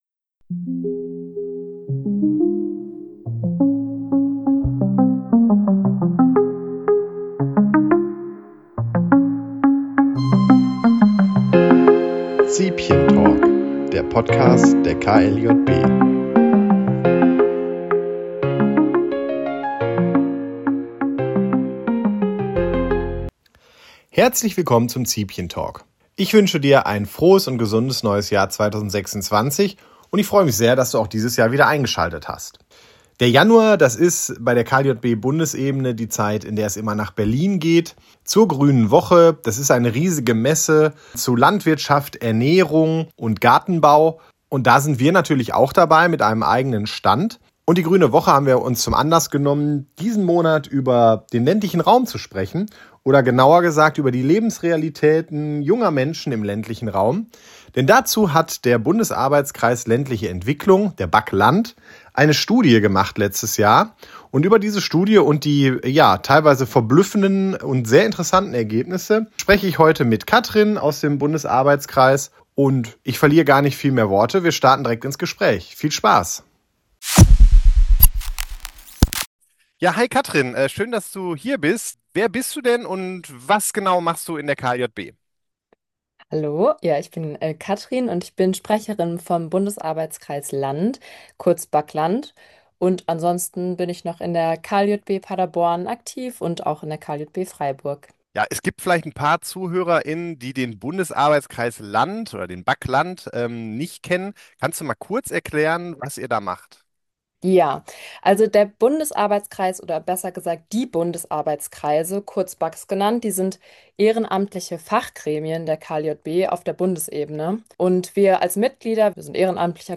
Gemeinsam sprechen wir über zentrale Ergebnisse zu Mobilität, Gemeinschaft, Ehrenamt, Vielfalt und Teilhabe: Weshalb wollen viele junge Menschen auf dem Land bleiben?